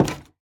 Minecraft Version Minecraft Version 1.21.5 Latest Release | Latest Snapshot 1.21.5 / assets / minecraft / sounds / block / cherrywood_door / toggle3.ogg Compare With Compare With Latest Release | Latest Snapshot